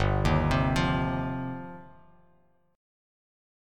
G7sus4#5 chord